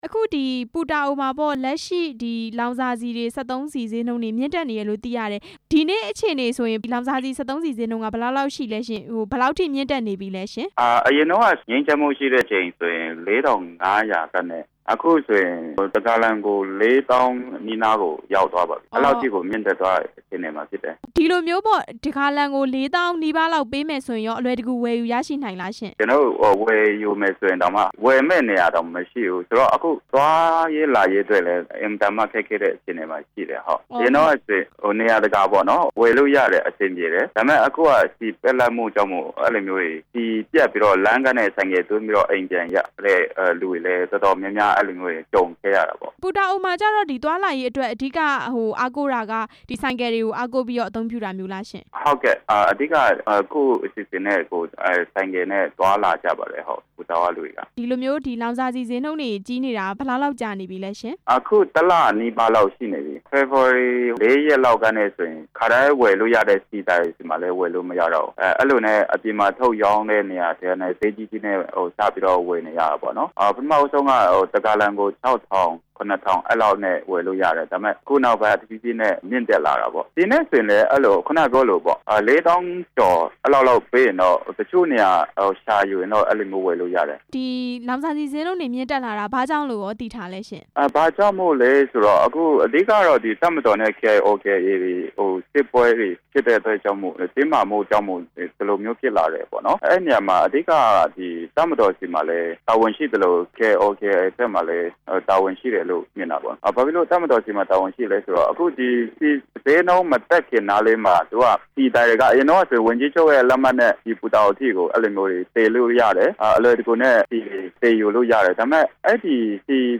ပူတာအိုမြို့နယ်မှာ ဓါတ်ဆီဈေးမြင့်တက်နေတဲ့အကြောင်း မေးမြန်းချက်